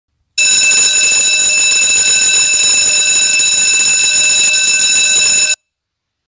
dzwonek.mp3